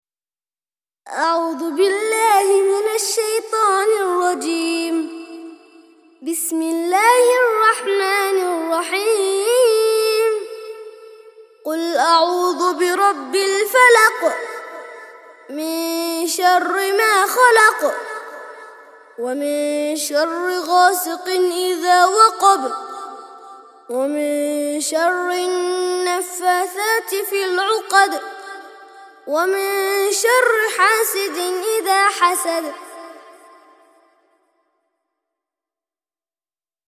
113- سورة الفلق - ترتيل سورة الفلق للأطفال لحفظ الملف في مجلد خاص اضغط بالزر الأيمن هنا ثم اختر (حفظ الهدف باسم - Save Target As) واختر المكان المناسب